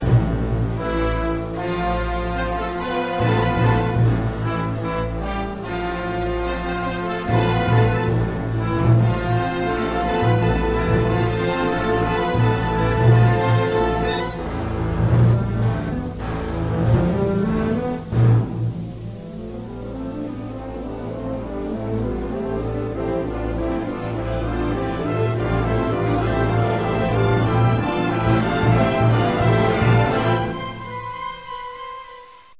Partitions pour orchestre d'harmonie + chœur optionnel SSA.
• (Normandy, 1944) - Tone Poem For Band - Opus 83.